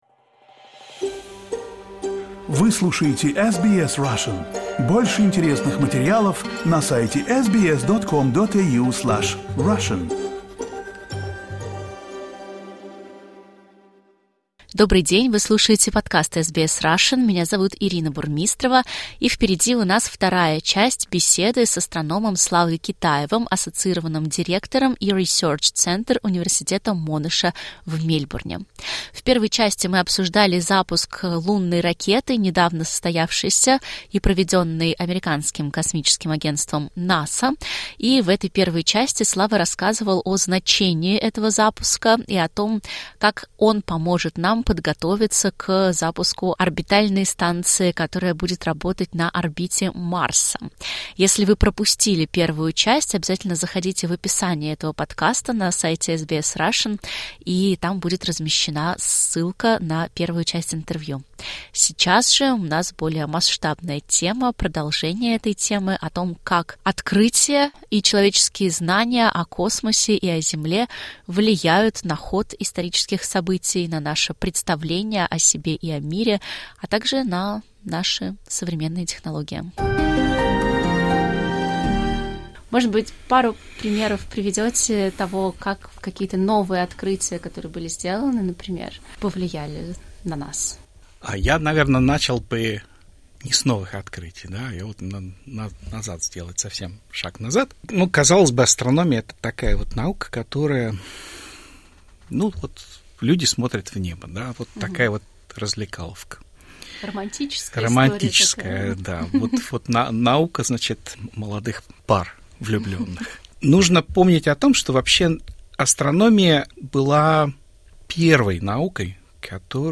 SBS Первую часть этого интервью